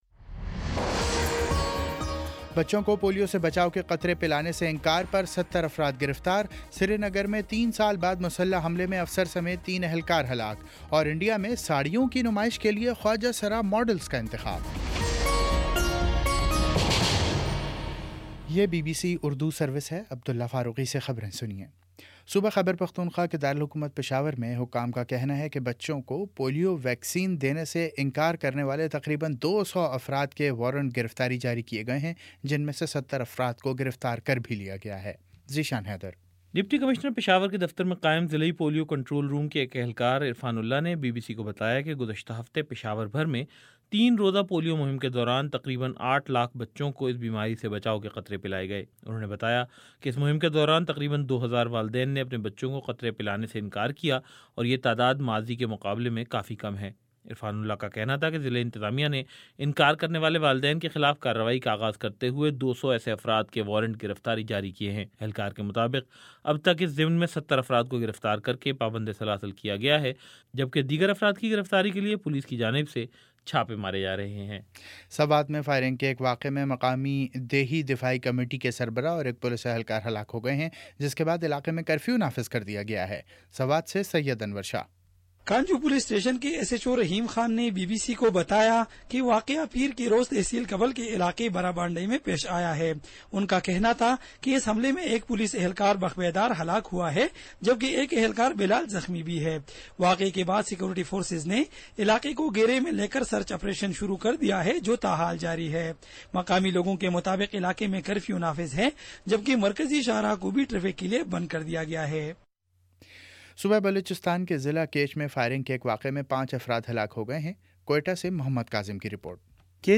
مئی 23 : شام چھ بجے کا نیوز بُلیٹن